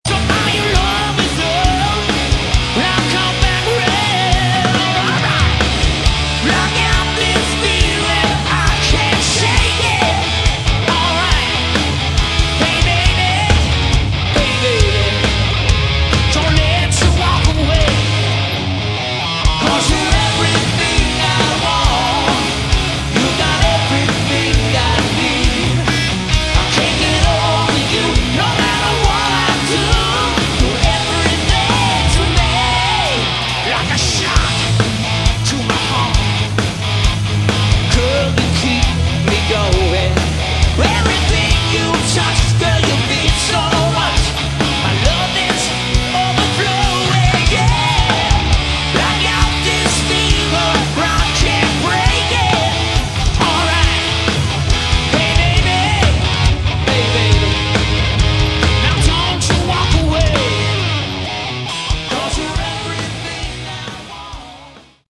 Category: Hard Rock / Melodic Rock
keyboards, vocals
guitar, backing vocals
bass
drums